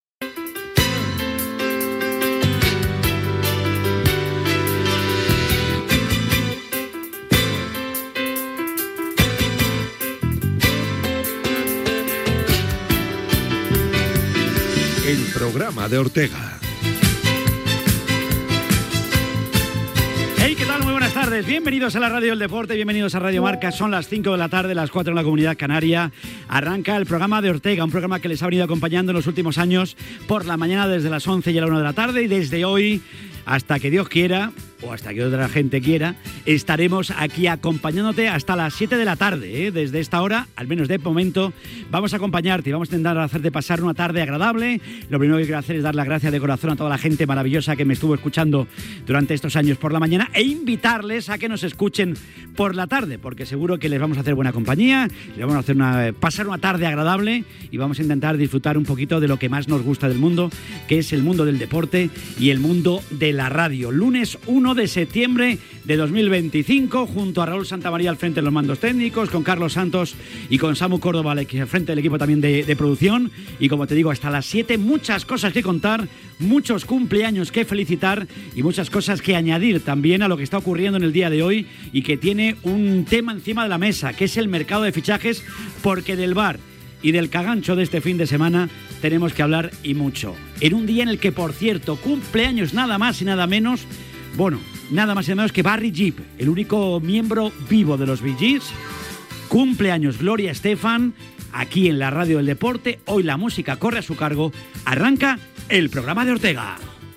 Careta del programa, presentació en l'inici de la temporada 2025-2026 amb avís del canvi d'horari.
Entreteniment